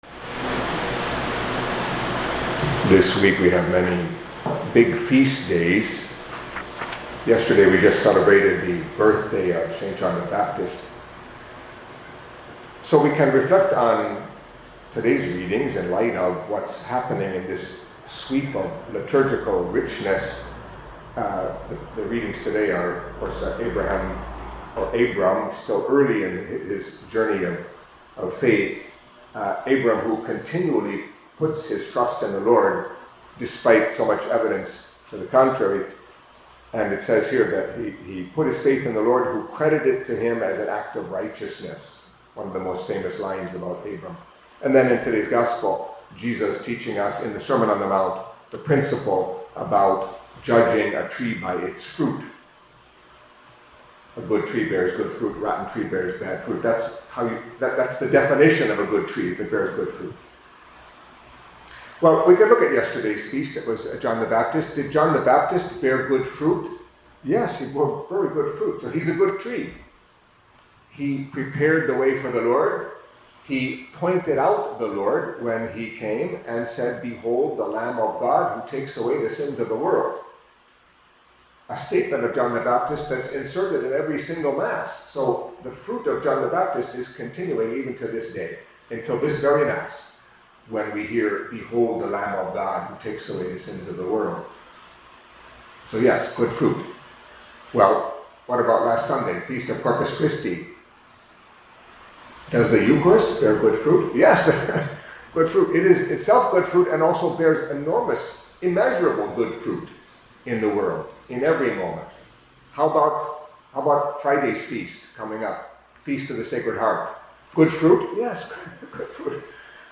Catholic Mass homily for Wednesday of the Twelfth Week in Ordinary Time